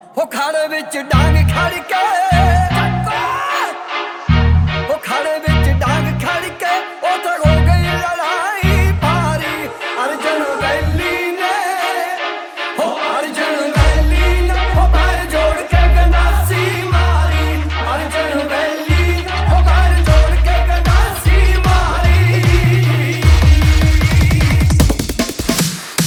Remix Ringtones